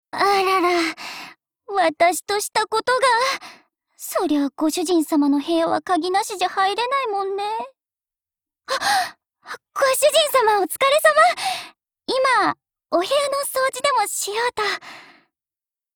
贡献 ） 分类:碧蓝航线:曼彻斯特语音 2022年11月19日